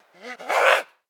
DayZ-Epoch/SQF/dayz_sfx/zombie/spotted_6.ogg at 1de402185a5713de0a3337b151d9756e8ab9925f
spotted_6.ogg